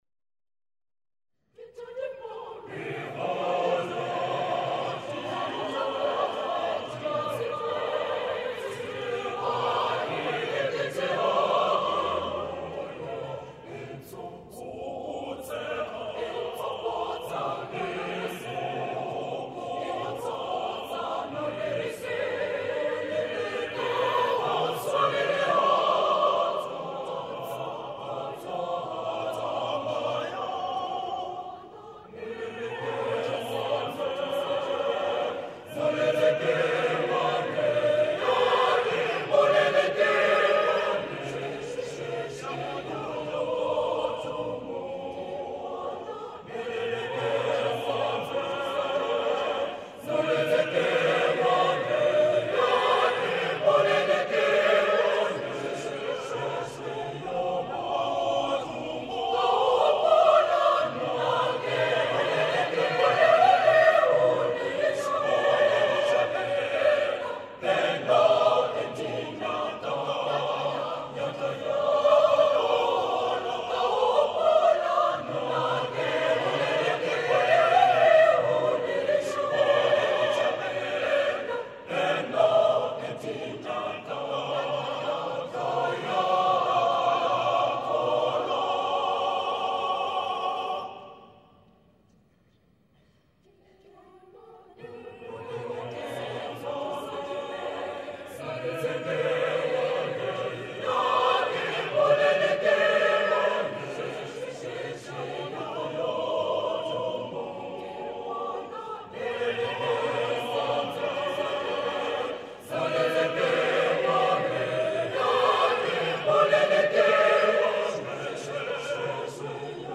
choir SATTB